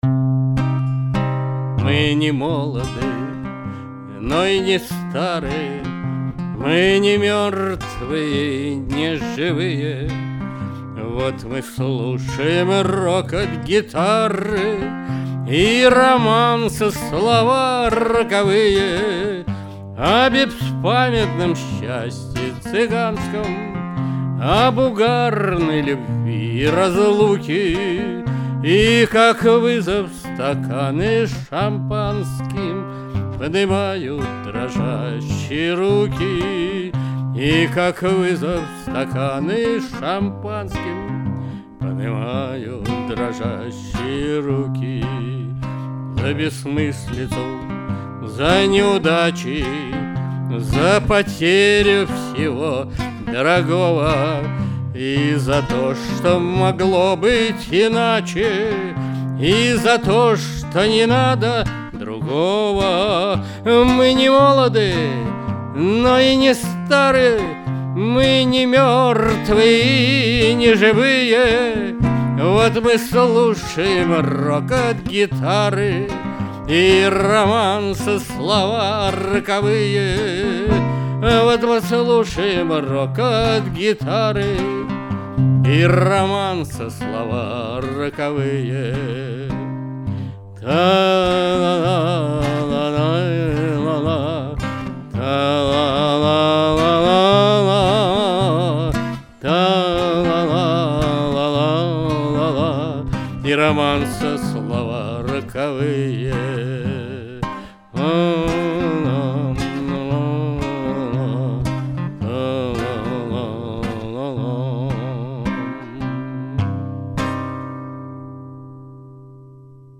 вокал, гитара
Записано в студии